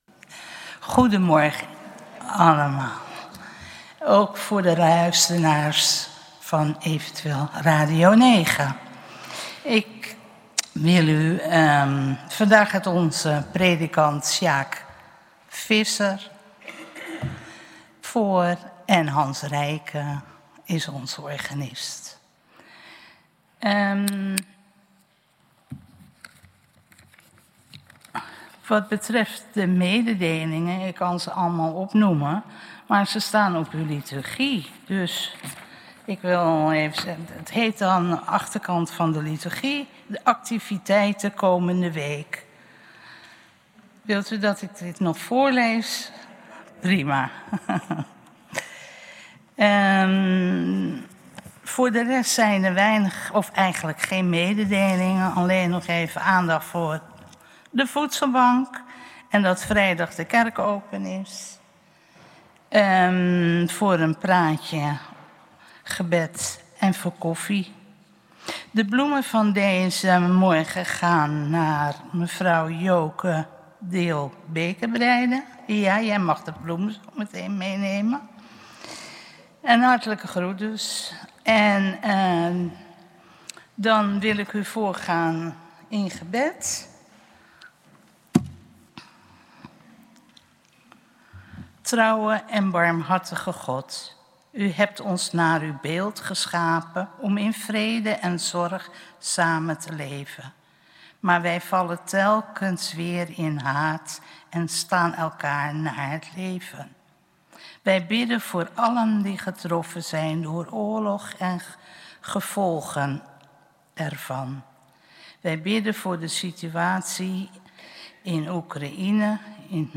Kerkdienst geluidsopname